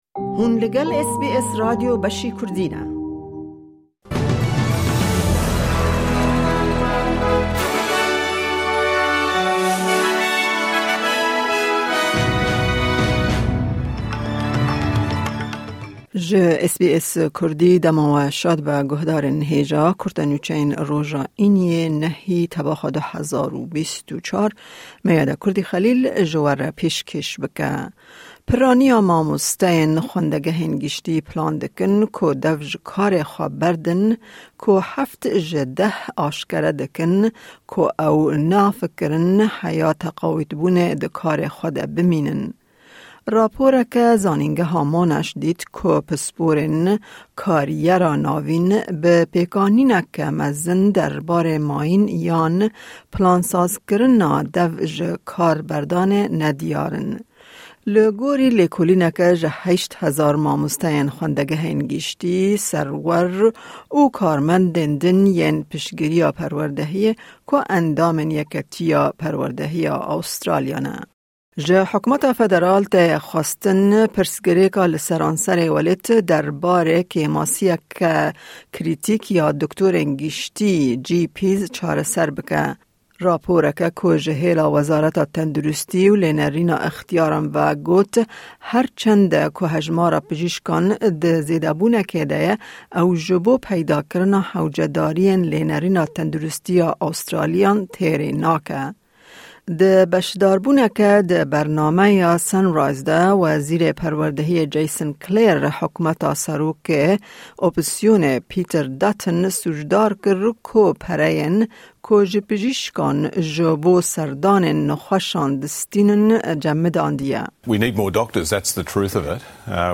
Kurte Nûçeyên roja Înî 9î Tebaxa 2024